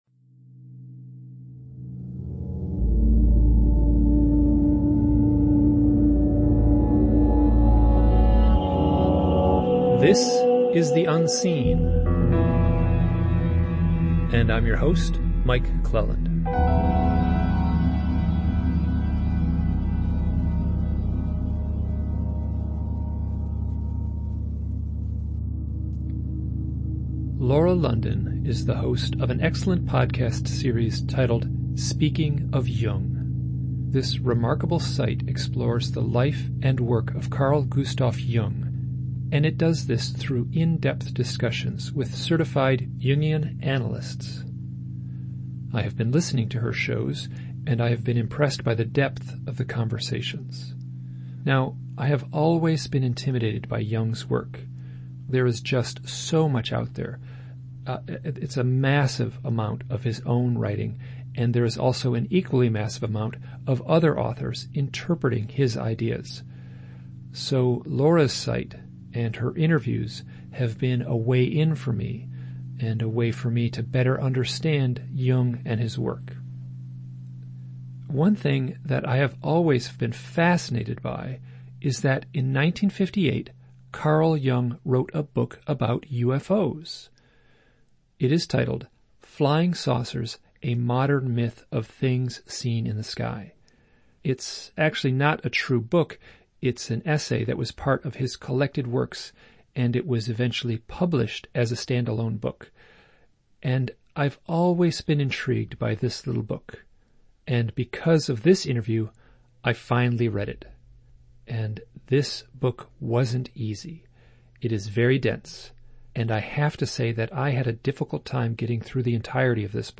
Carl Jung wrote a book about UFOs in 1958, it’s titled FLYING SAUCERS, A Modern Myth of Things Seen in the Sky. Pare of this show is a discussion of the ideas in this book.